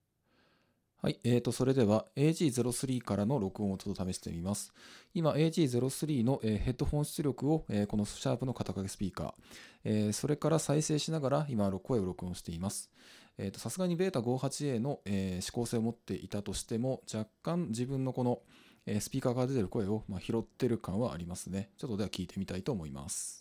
今度はBeta58A+AG03で、AN-SX7Aでモニタしながら録音したもの